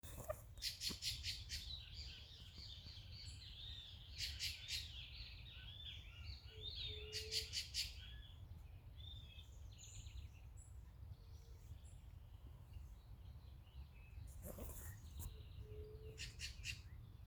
Sabiá-barranco (Turdus leucomelas)
Nome em Inglês: Pale-breasted Thrush
Localidade ou área protegida: Reserva Privada San Sebastián de la Selva
Condição: Selvagem
Certeza: Fotografado, Gravado Vocal
Zorzal-sabia-1_1.mp3